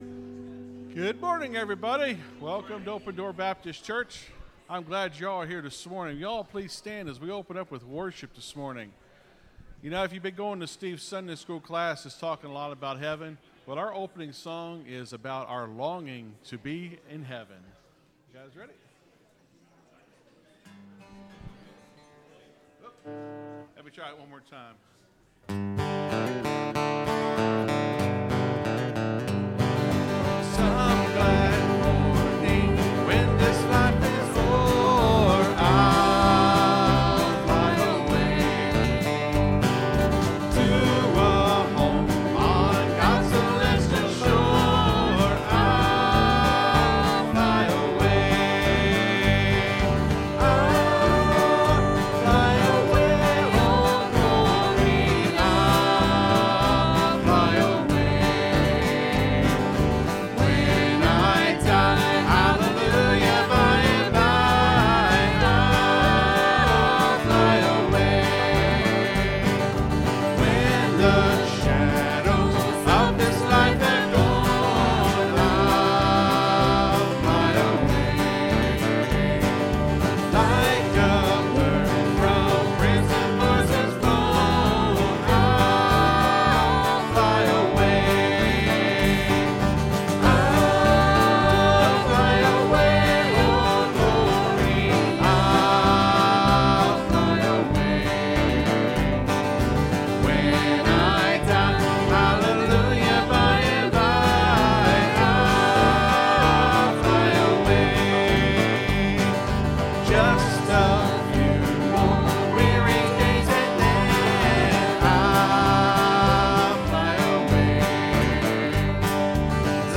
(Sermon starts at 38:10 in the recording).